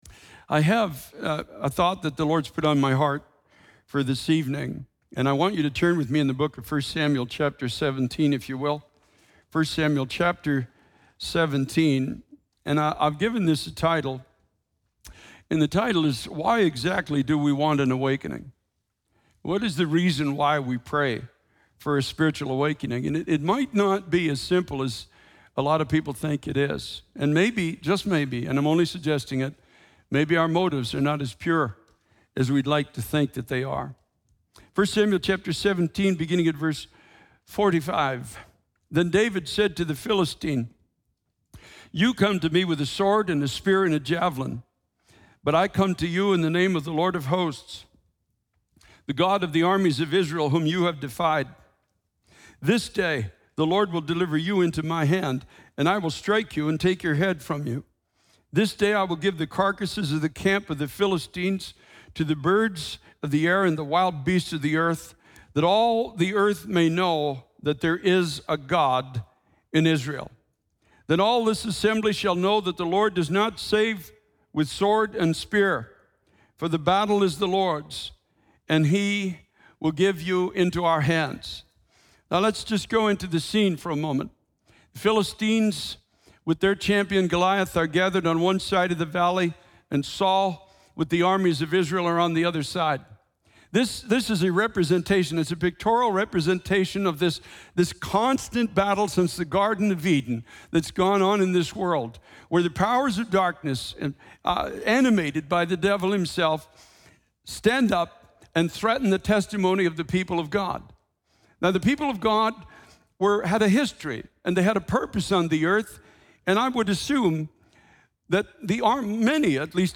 In this sermon, the preacher discusses the battle between the Philistines and the armies of Israel, symbolizing the ongoing battle between the powers of darkness and the people of God.